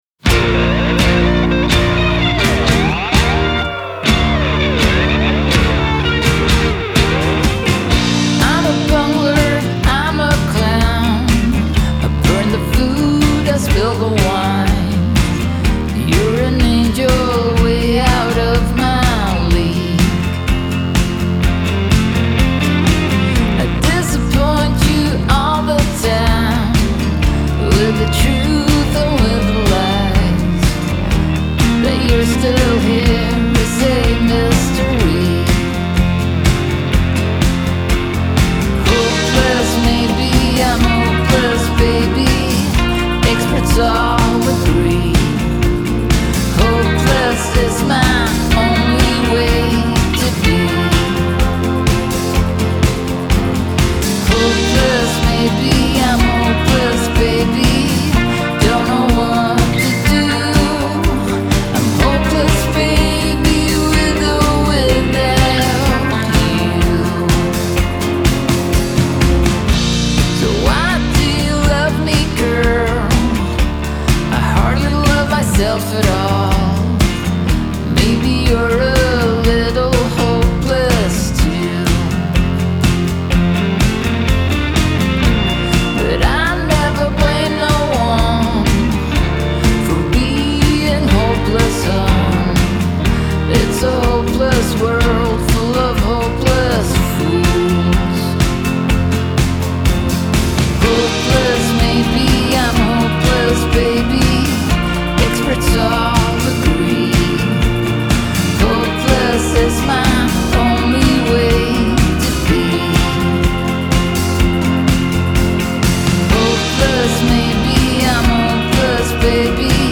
Genre : Indie Rock, Indie Pop